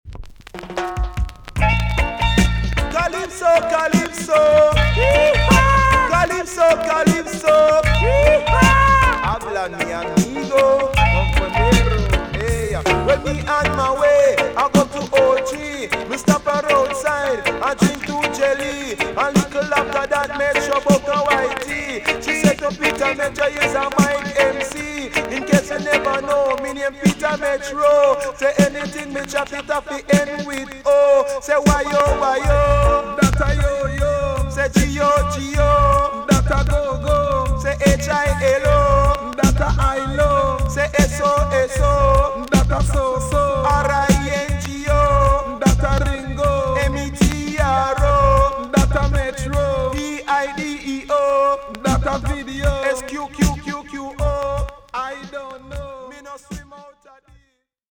TOP >80'S 90'S DANCEHALL
VG+ 軽いプチノイズがあります。
NICE RUB A DUB DJ TUNE!!